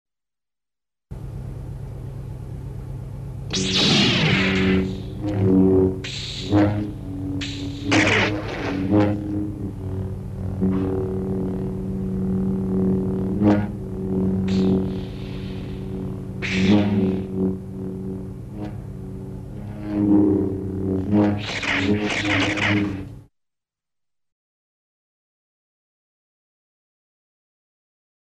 14 Light Saber and Blaster.mp3